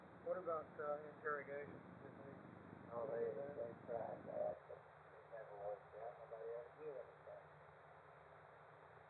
Sort of flutter noise removal
I recorded the source from a cassette tape from 1990 using audacity (2.3.3-1build1) and then changed to the alpha version. The recording has several things done to it to filter out noise and improve the sound.
It starts at about 1.6s into the attached recording and ends after the attachment for a total of 10.421s. Is there some way to remove it and leave the spoken words?
The motorboating sound is made up of a bunch of individual tones that have odd relationships to each other.
In Hz. 100, 125, 150, 180, 200, 225, 250, 275, 325, 350, etc, etc.